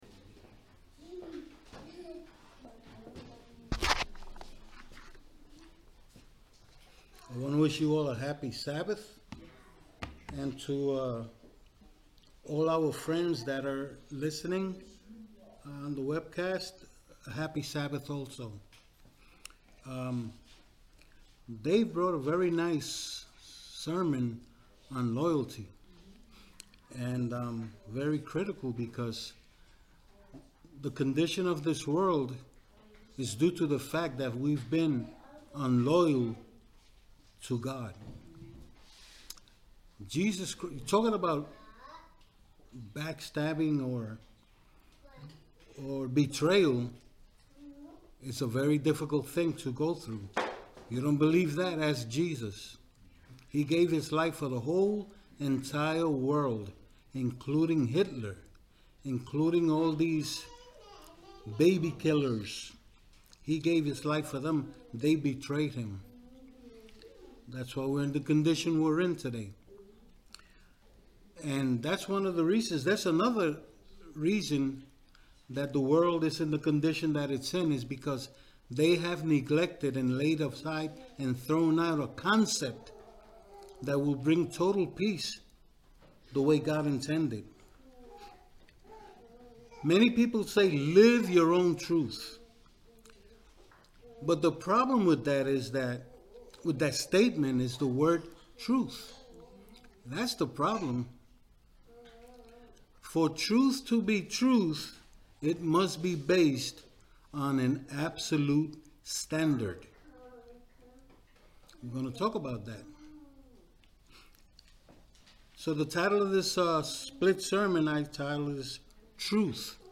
Sermons
Given in Ocala, FL